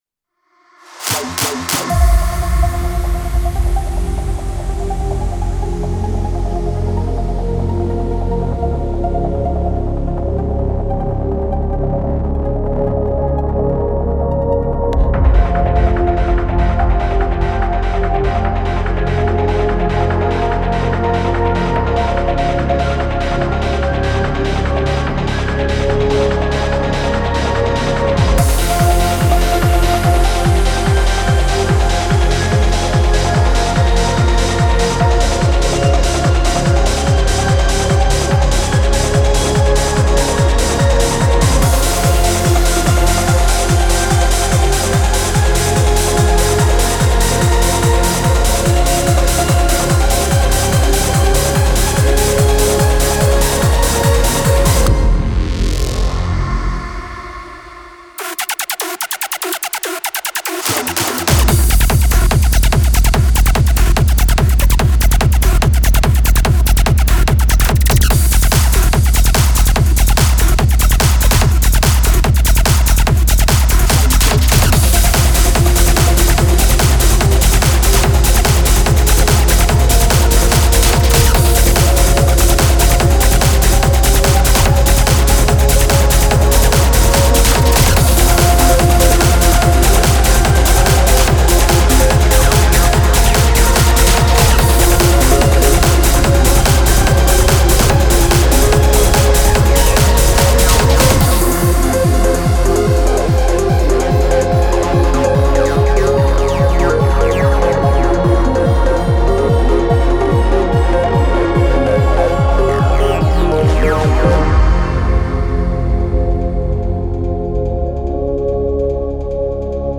Download Instrumental Version